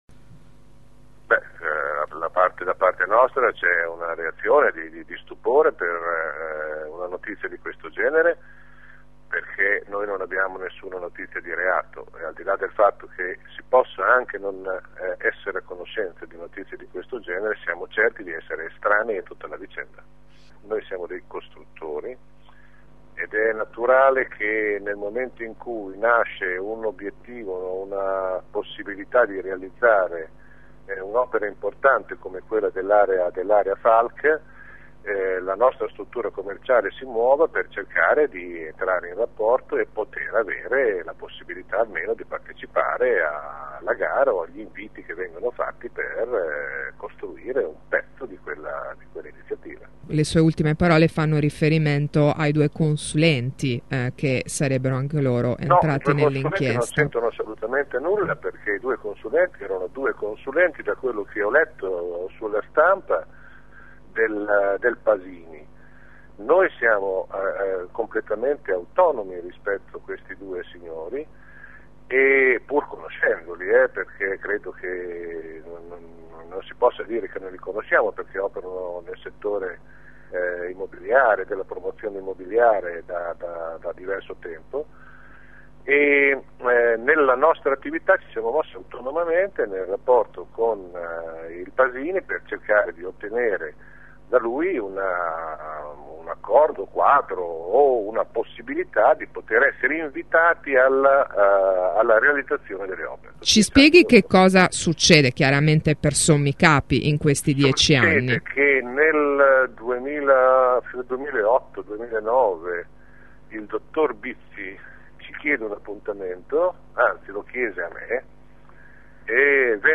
Intervistato ai nostri microfoni